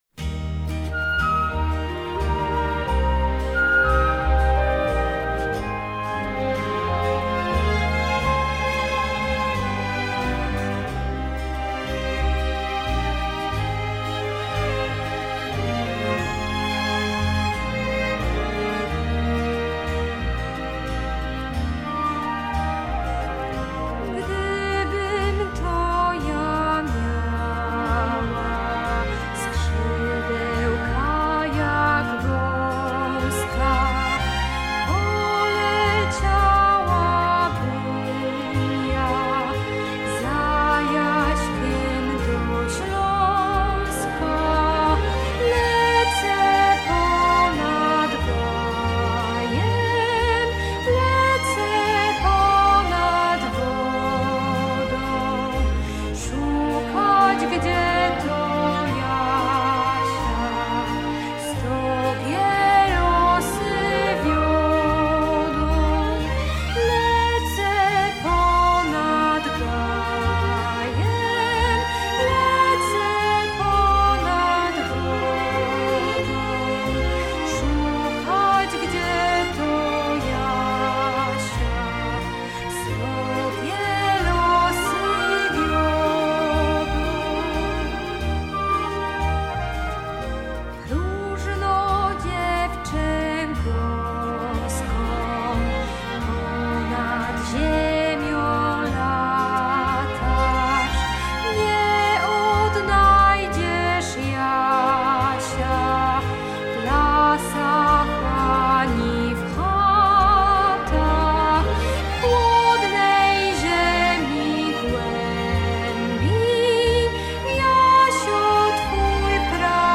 Aranżacje orkiestrowe